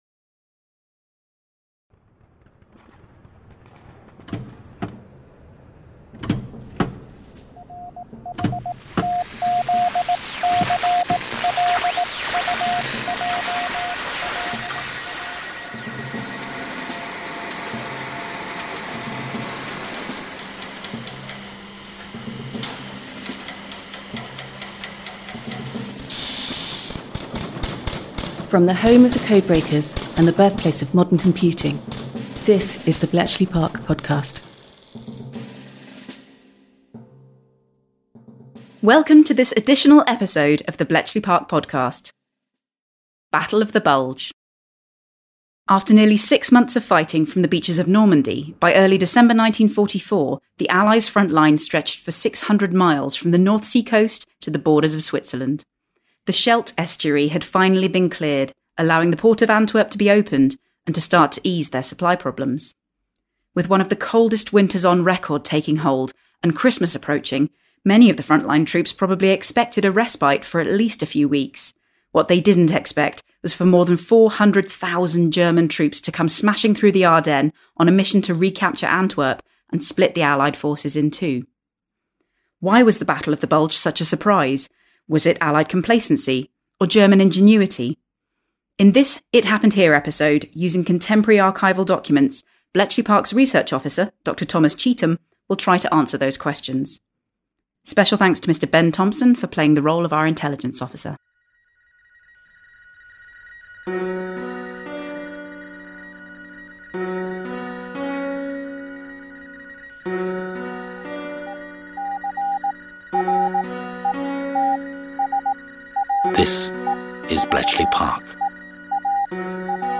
playing the role of our Intelligence Officer.